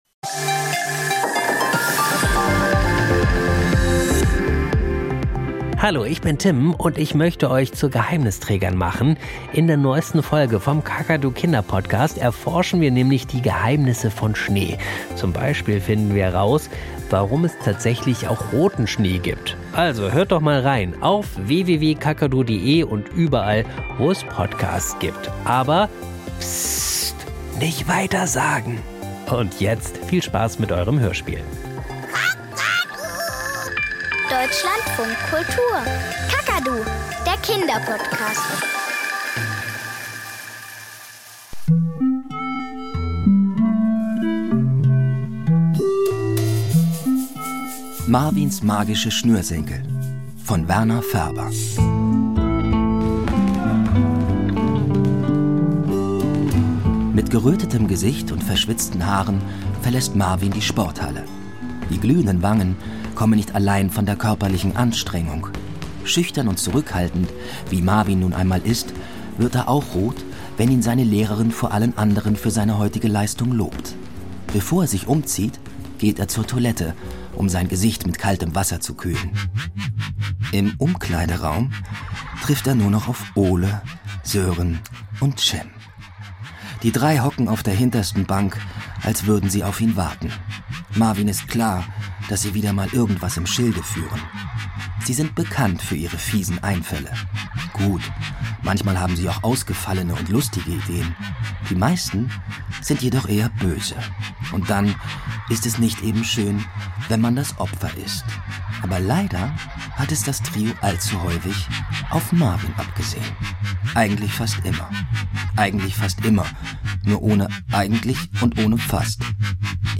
Kinderhörspiel und Geschichten - Marvins magische Schnürsenkel